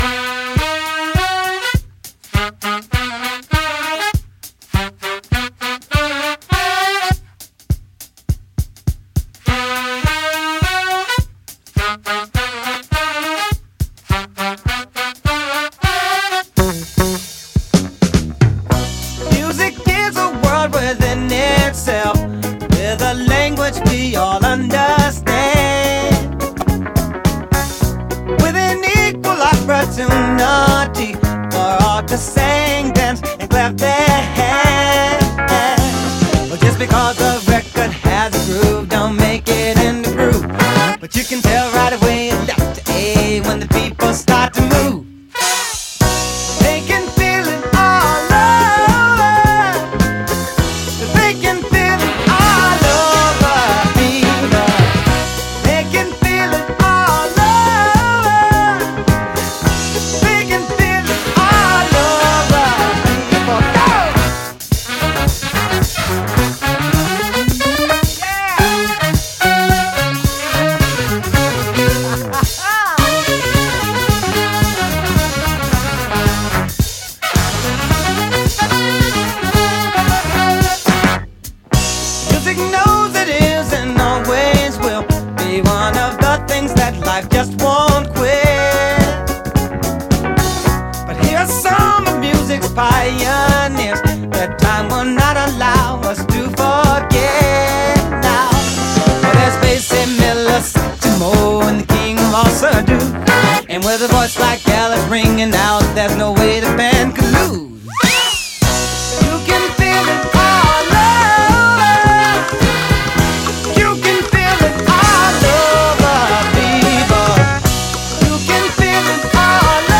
Mέτρο τεσσάρων τετάρτων εικόνα
Οι χτύποι τονίζονται σταθερά ανά τέσσερις.